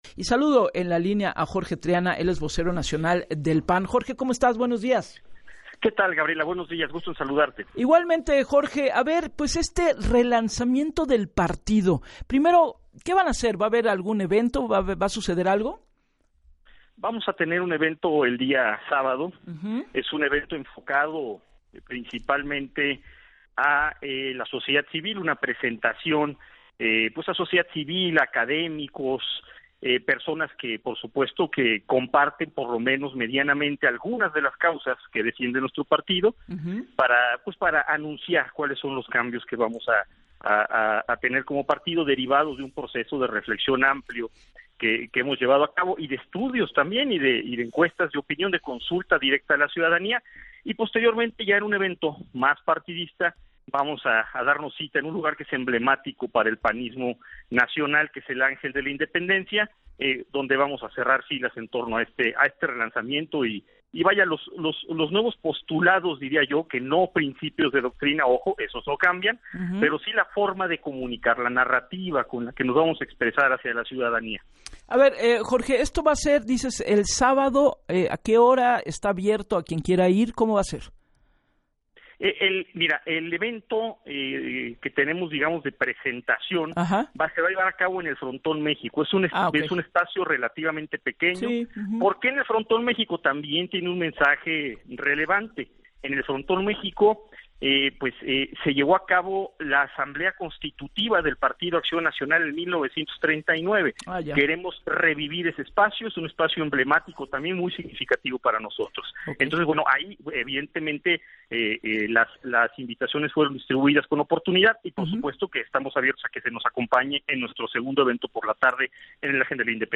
En entrevista con Gabriela Warketin para “Así las Cosas”, el panista reconoció “errores cometidos” y seis años de denostaciones desde el poder, por lo que ahora dijo, “queremos nosotros ser el portavoz de muchos sectores olvidados por este gobierno, queremos ser la voz de las madres buscadoras que se les da un portazo en Palacio Nacional, que no se les escucha, queremos ser la voz de los padres de niños con cáncer que se les deja sin medicamentos y se les complica la vida para conseguirlos más aún con la aprobación de esta nueva Ley de Amparo, queremos ser el portavoz de los trabajadores del poder judicial, que por un cálculo eminentemente político y de acumulación del poder han sido excluidos de sus plazas laborales”.